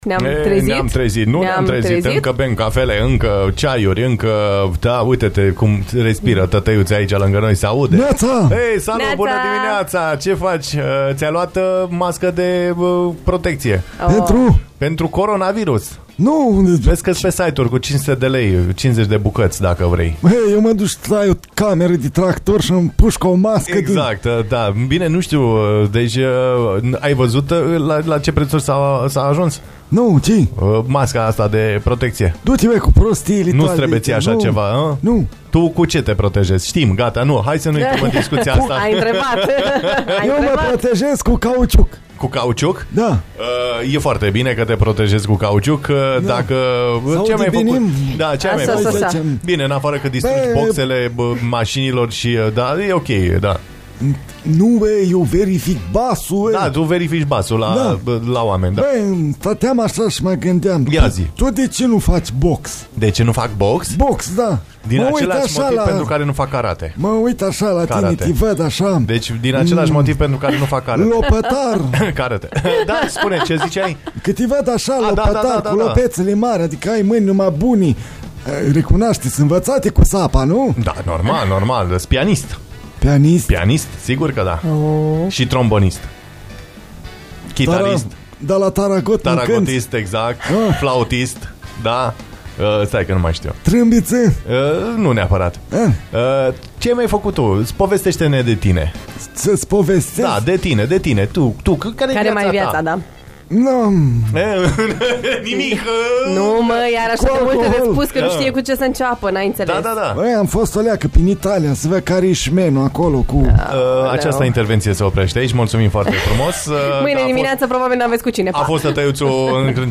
Întregul dialog, mai jos.